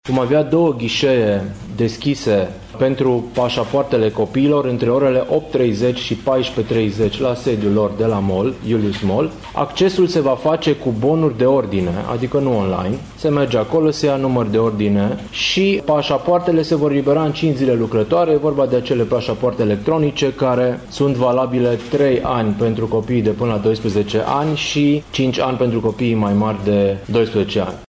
Prefectul de Timiș precizează că deschiderea celor două ghișee dedicate copiilor vine în sprijinul părinților, care pot profita de ziua liberă pentru a obține pașaportul necesar plecării cu copiii în vacanță în afara țării.
Mihai-Ritivoiu-pasapoarte-1-iunie.mp3